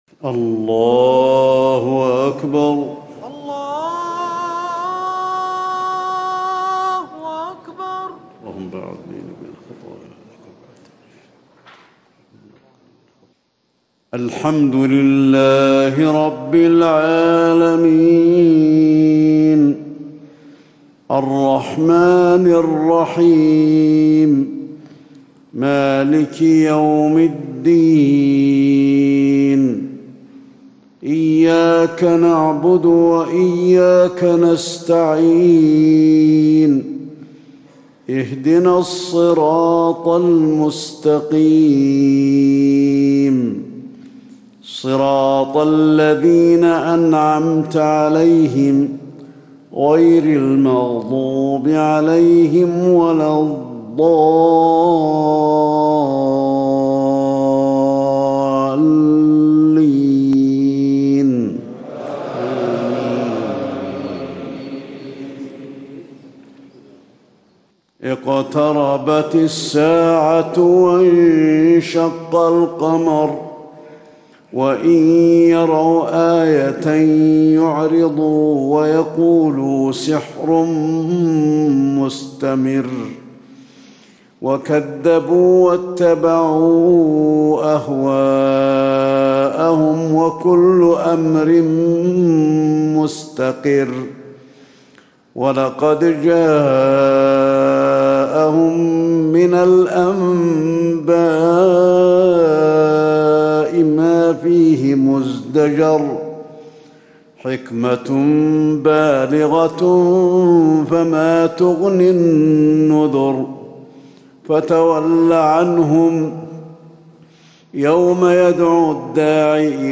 صلاة الفجر 9 ذو الحجة 1437هـ سورة القمر > 1437 🕌 > الفروض - تلاوات الحرمين